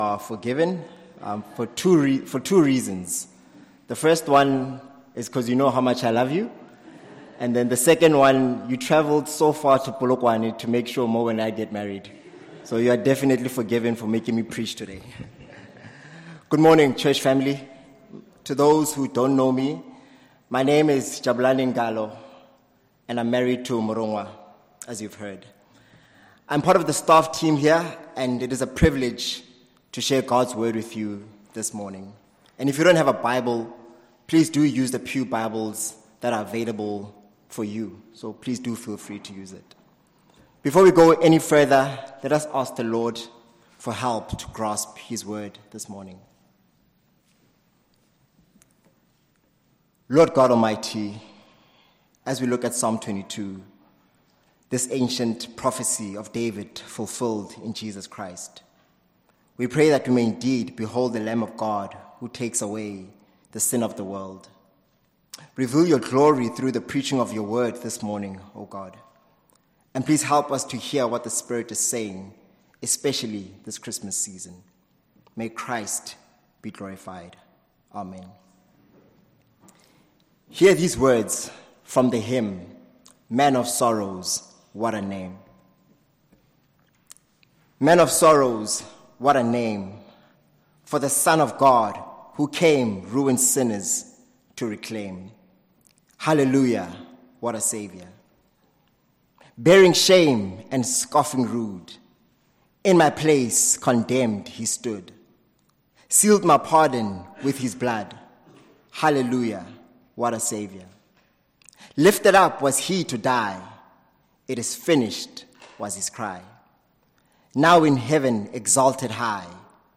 Christ the king Passage: Psalm 22 Service Type: Morning Service Topics